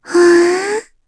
Lavril-Vox_Thinking_kr.wav